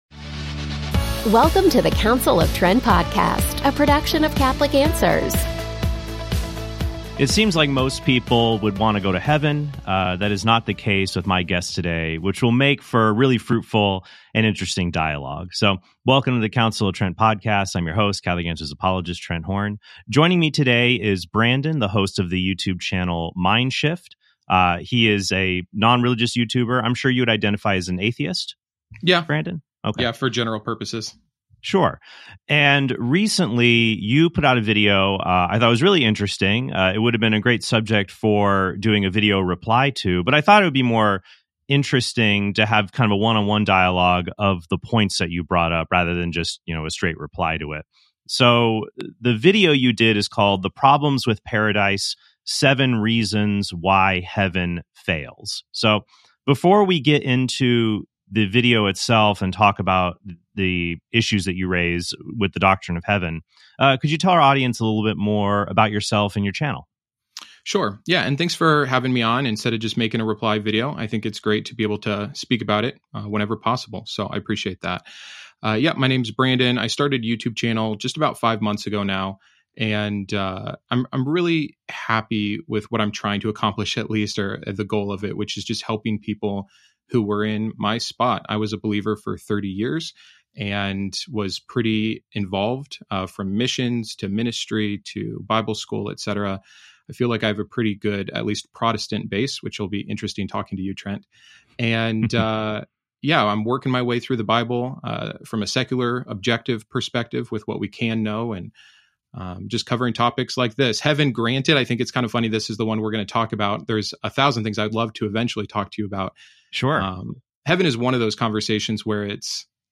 DIALOGUE: Would Heaven Be Hellish?